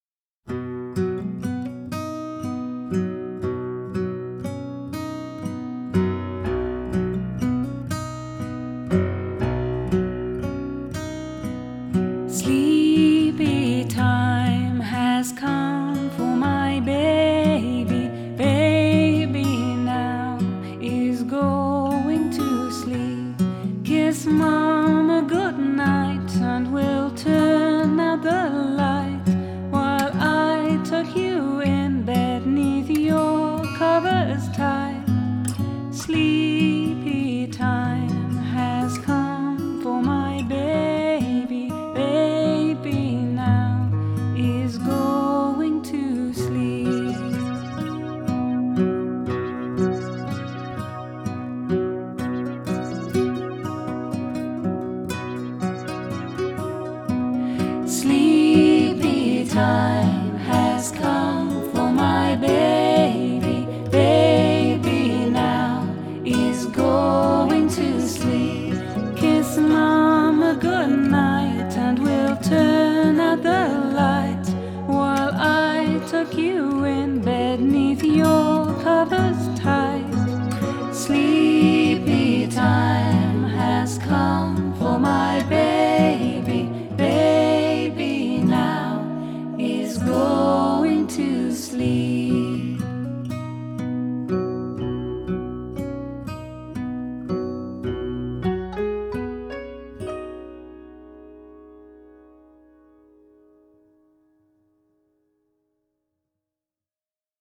Lullaby
Our recording treats it with the quiet care it deserves.